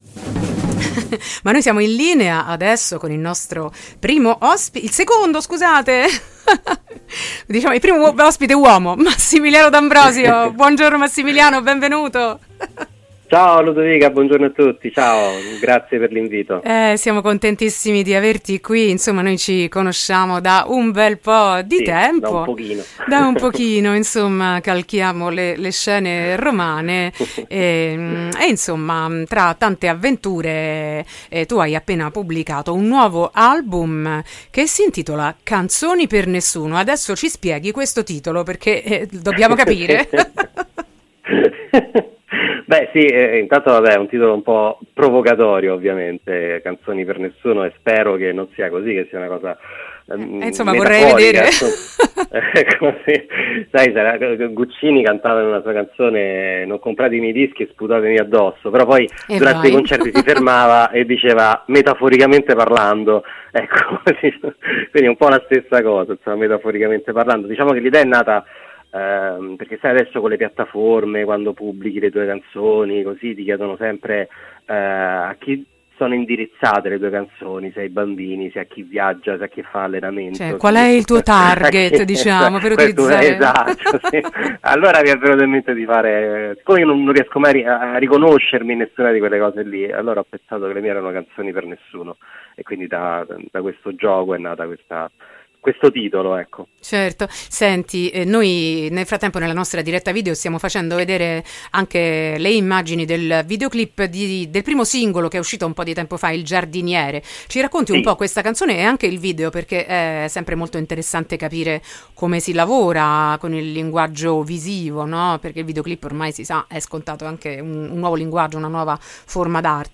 Canzoni per nessuno: intervista